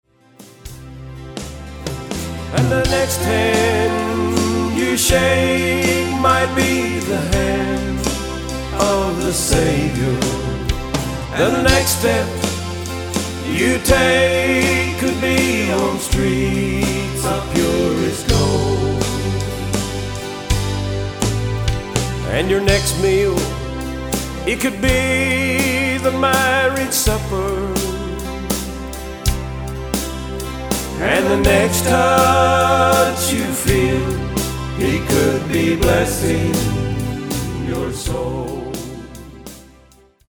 Autoharp
Drums, Lead and Harmony Vocals
Guitar
Bass
Keyboards
Rhythm guitar